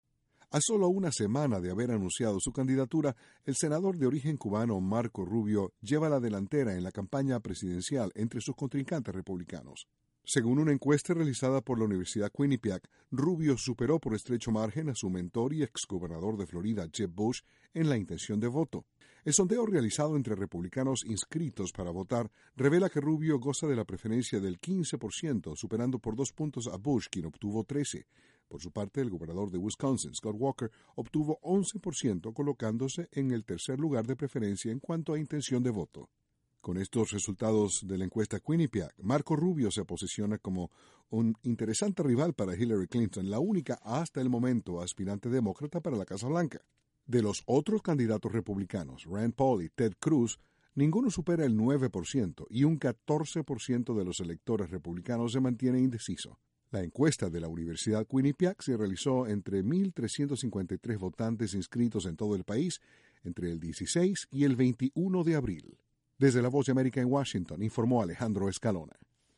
Según la encuesta Quinipiac, el senador de Florida Marco Rubio se perfila como un serio rival de Hillary Clinton. Desde la Voz de América, en Washington